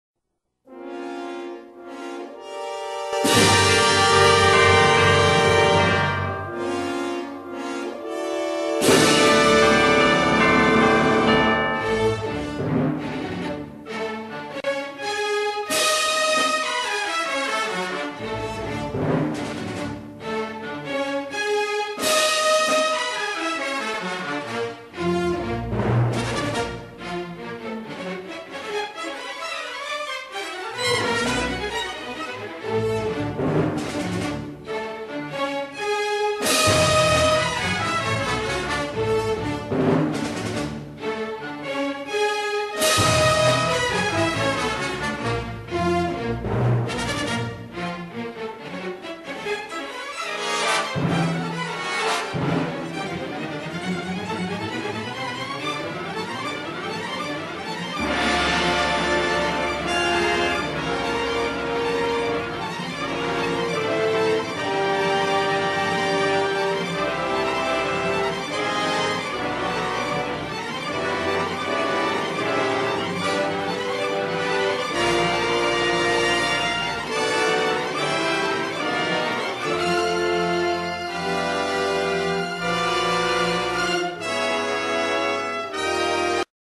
Cabe subrayar la estridente música